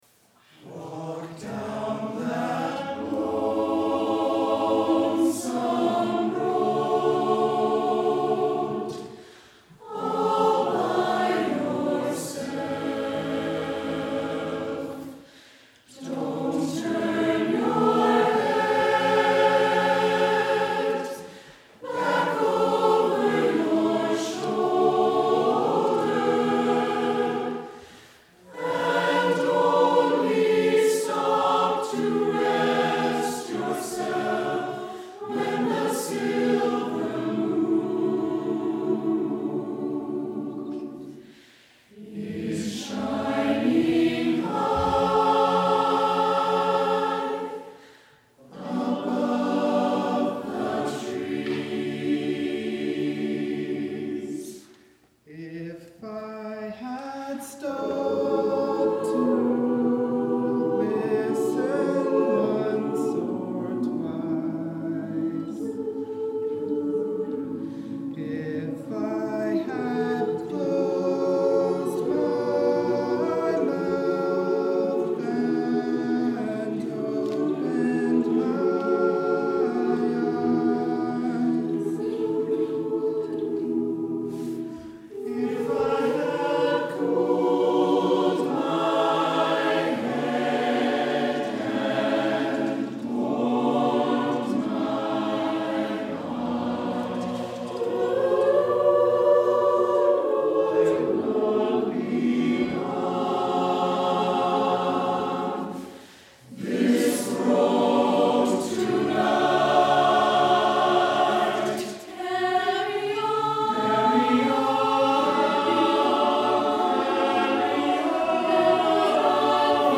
Below you will find select audio and video from our past performances for your listening and viewing enjoyment.
From our January 30, 2016 concert, Journeys: